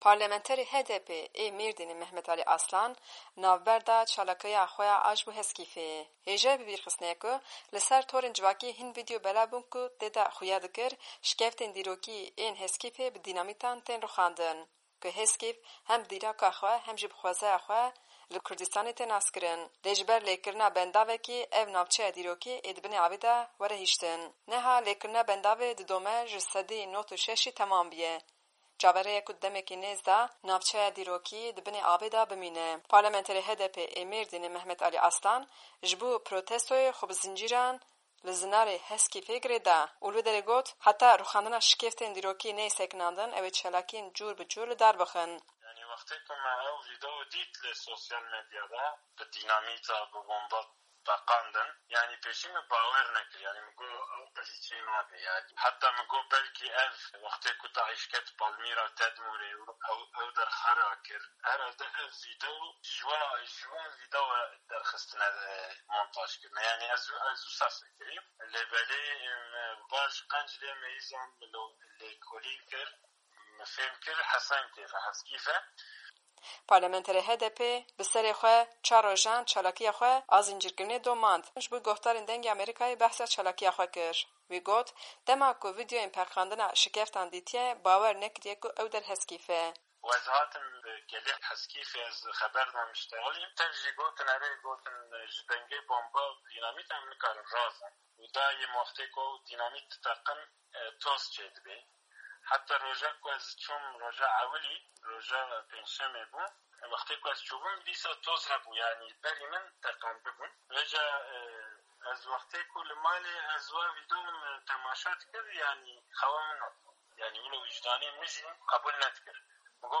Aslan ji DengêAmerîka re behsa çalakîya xwe kir.